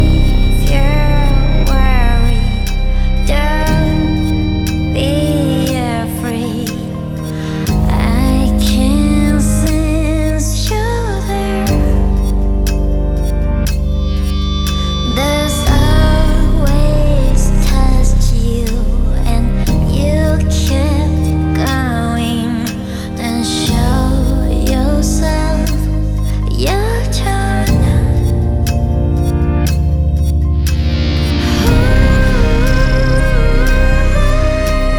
Жанр: Музыка из фильмов / Саундтреки